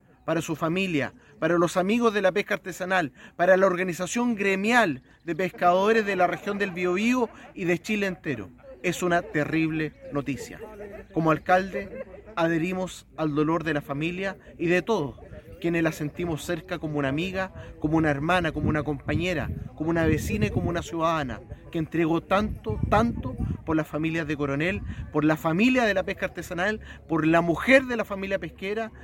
El alcalde de la comuna, Boris Chamorro, lamentó la muerte y la catalogó como una terrible noticia para la comuna.